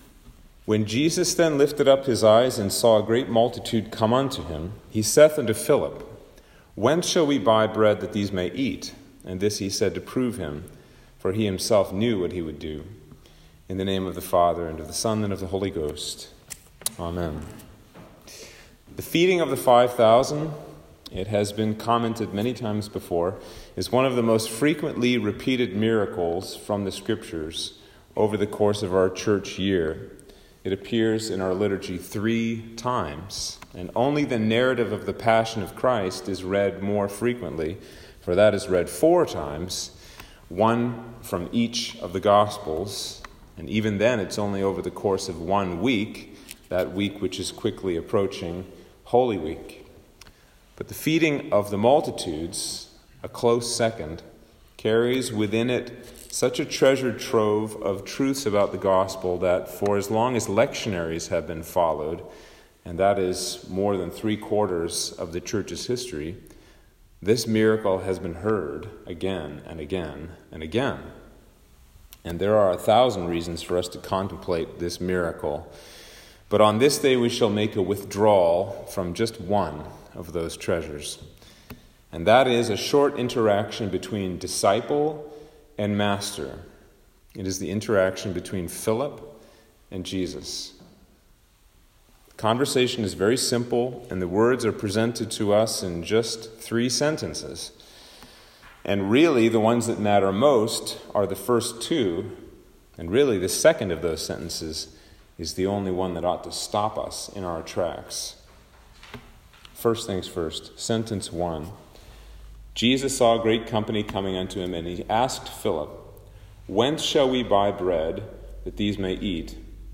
Sermon for Lent 4
Sermon-for-Lent-4-2021.m4a